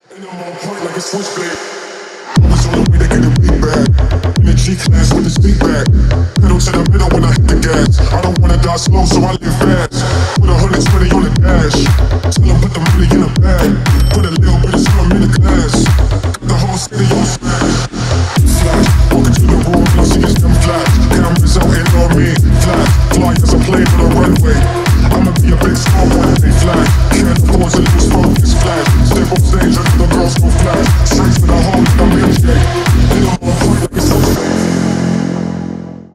Рэп и Хип Хоп
Танцевальные
клубные
громкие